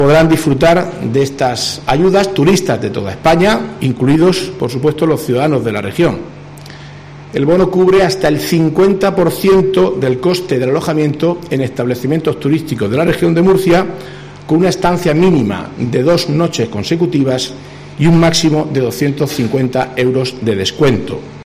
Marcos Ortuño, portavoz del Gobierno Regional